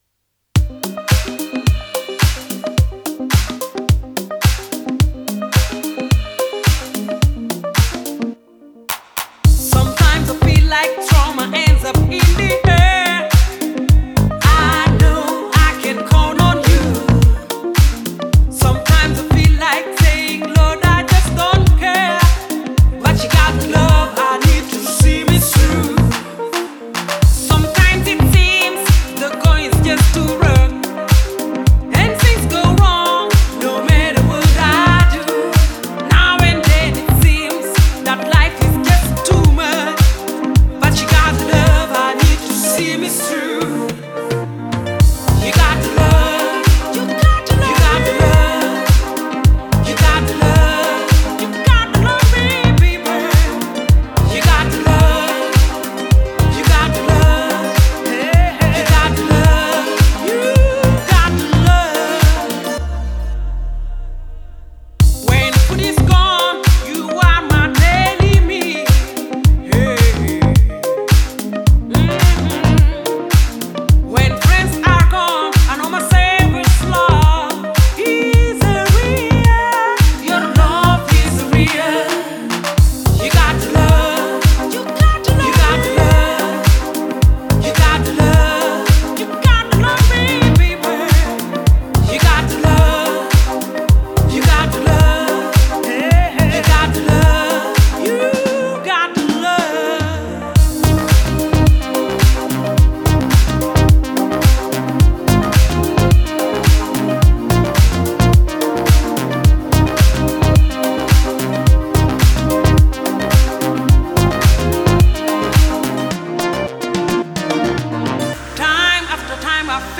а ритмичный бит заставляет двигаться в такт.